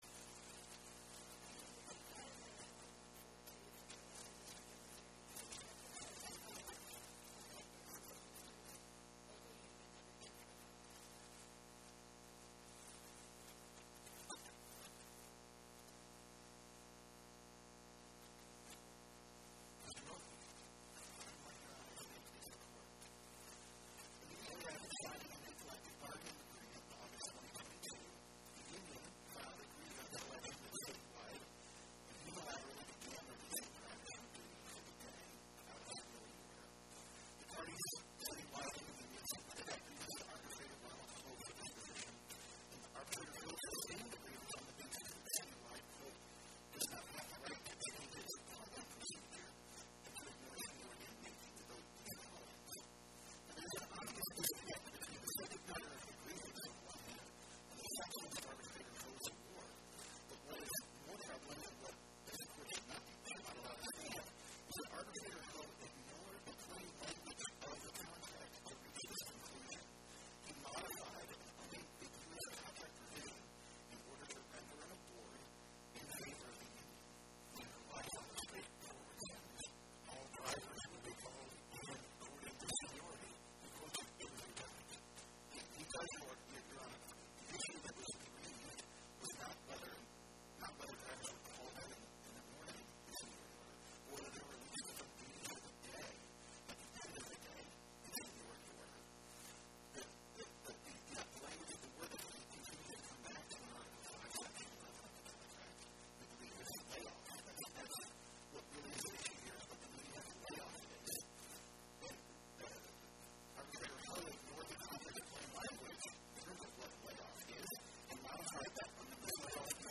Oral argument argued before the Eighth Circuit U.S. Court of Appeals on or about 11/20/2025